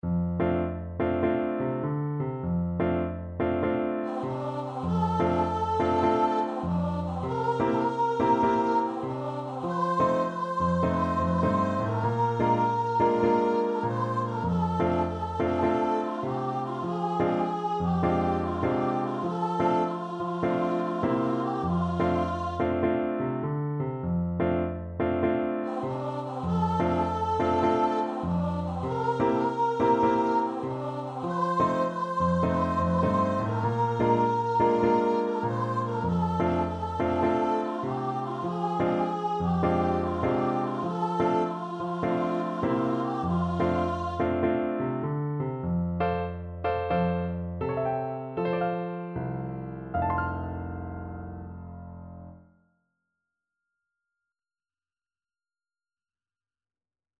Traditional Music of unknown author.
Moderate swing